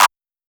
TM88 - CLAP (10).wav